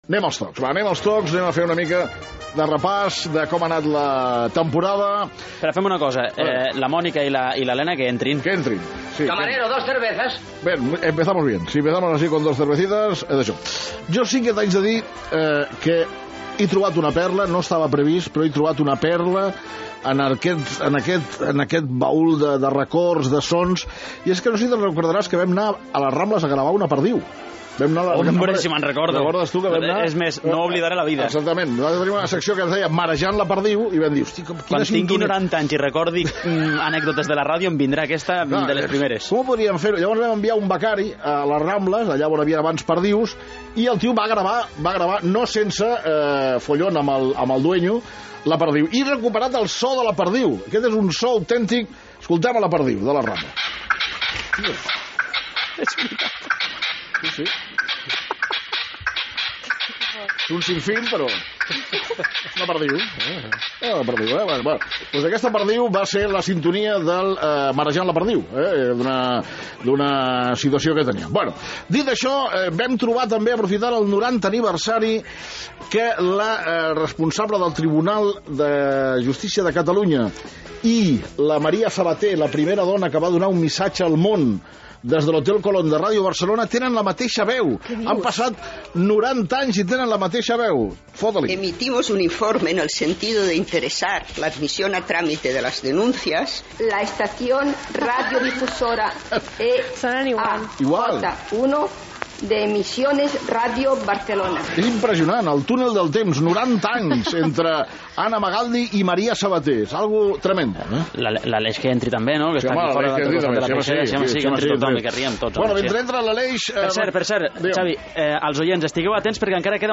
Secció "Els tocs" amb fragments radiofònics curiosos i divertits: el so d'una perdiu
Gènere radiofònic Esportiu